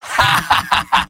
Robot-filtered lines from MvM. This is an audio clip from the game Team Fortress 2 .
{{AudioTF2}} Category:Medic Robot audio responses You cannot overwrite this file.
Medic_mvm_laughevil03.mp3